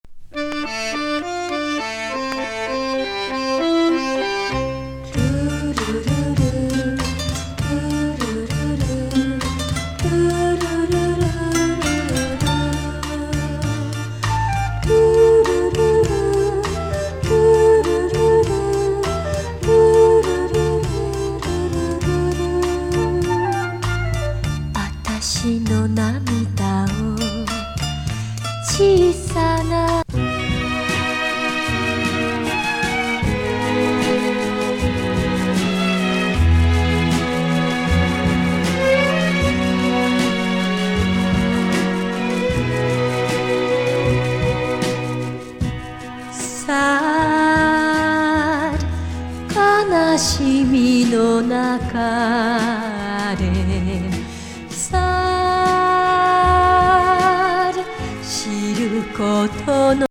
ベースラインも格好良い